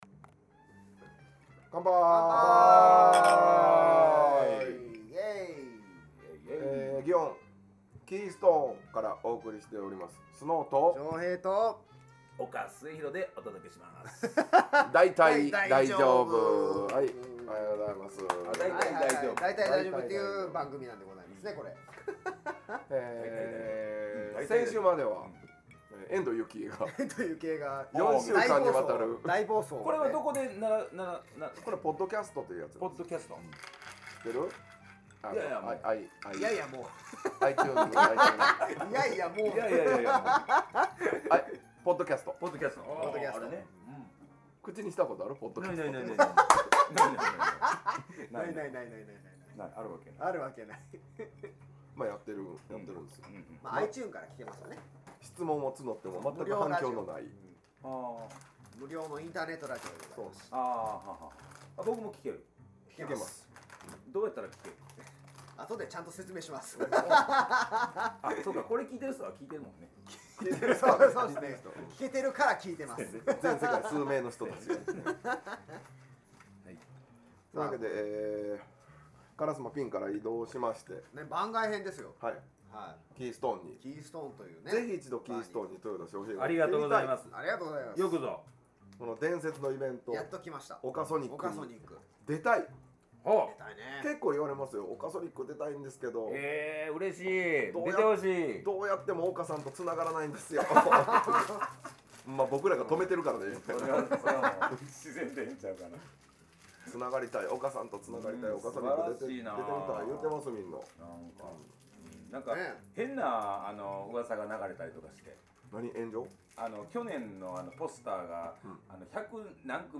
ギターも弾いちゃってますからね！
祇園にあるkeystoneというバーでまだまだ飲んでおるんですよ！